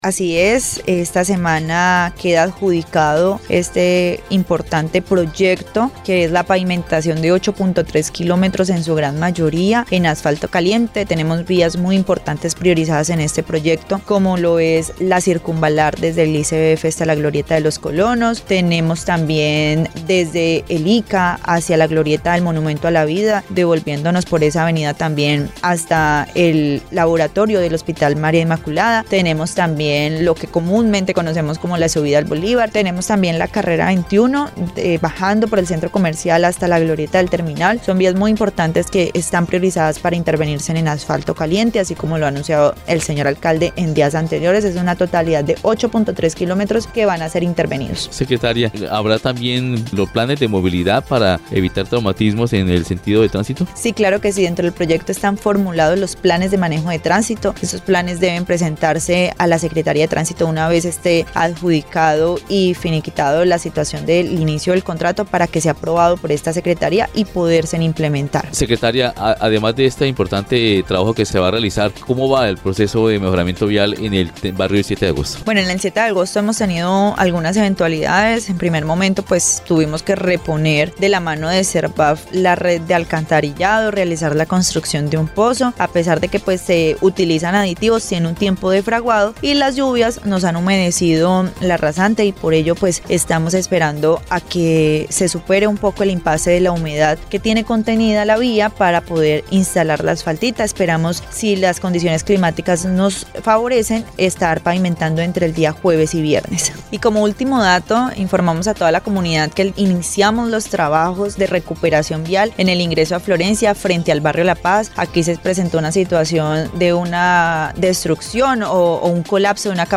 Anggy Méndez, secretaria de obras públicas encargada, dijo que el ingreso al barrio El Bolívar, Avenida Circunvalar hasta la plaza de mercado La Satélite y el tramo del ICA a la glorieta de La Vida en La Consolata, serán intervenidas con este contrato.